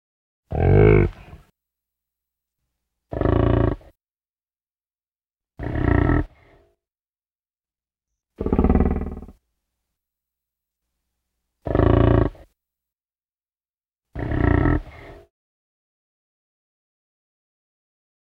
دانلود صدای بوفالو 1 از ساعد نیوز با لینک مستقیم و کیفیت بالا
برچسب: دانلود آهنگ های افکت صوتی انسان و موجودات زنده دانلود آلبوم صدای حیوانات وحشی از افکت صوتی انسان و موجودات زنده